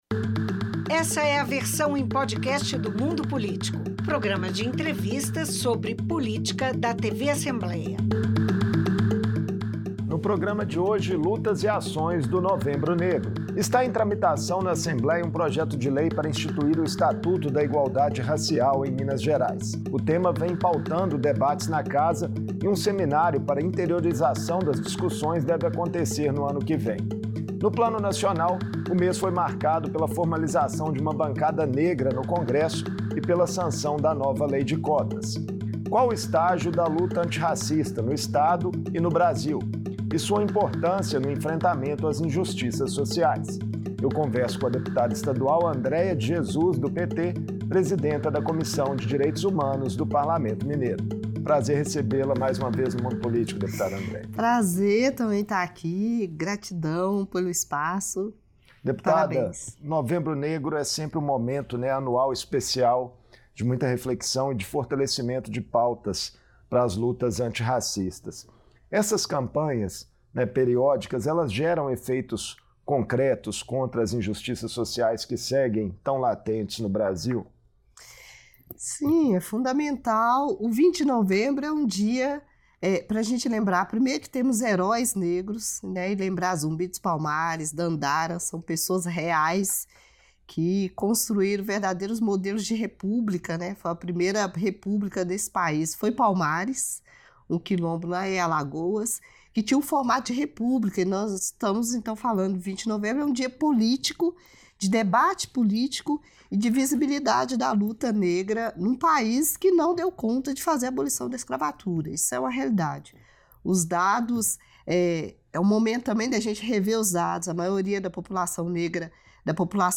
A deputada Andréia de Jesus (PT) é uma das autoras do projeto de lei que cria balizas para políticas públicas voltadas à população negra. No Mundo Político, ela fala sobre a importância de ações afirmativas e de campanhas como o Novembro Negro.